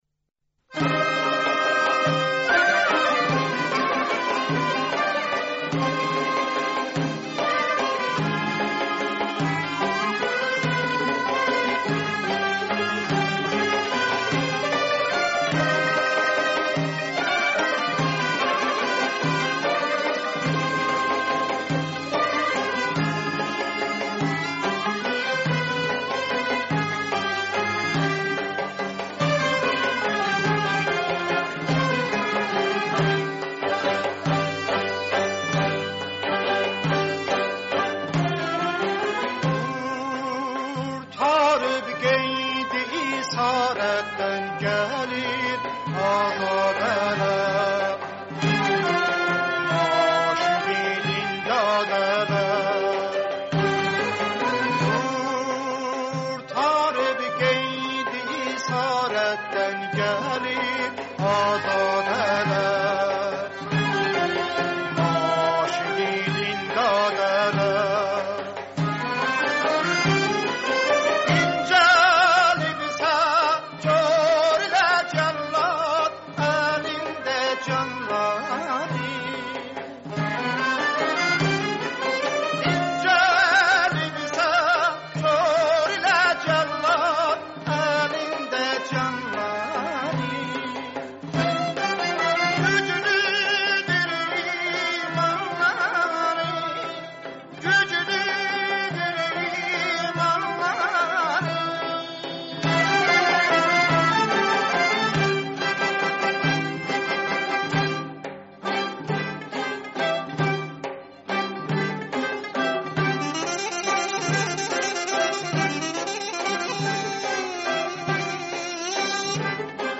سرودی آذری